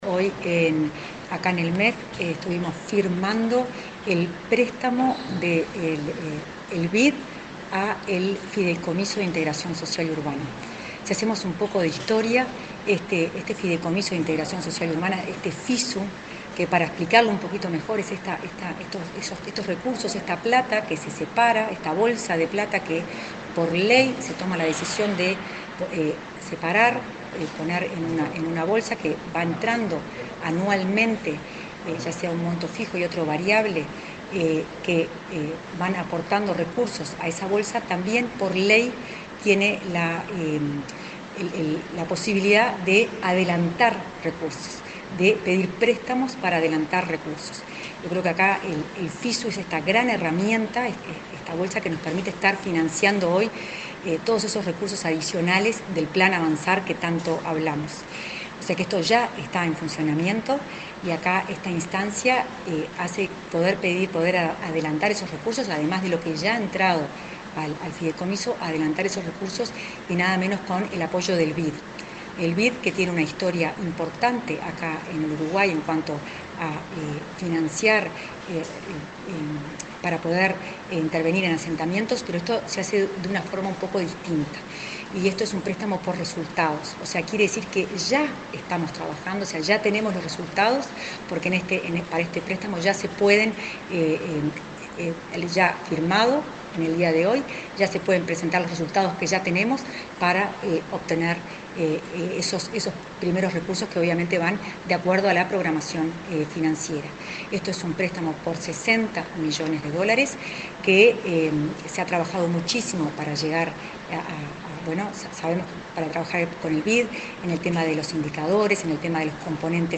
Entrevista a la directora de Integración Social y Urbana del MVOT, Florencia Arbeleche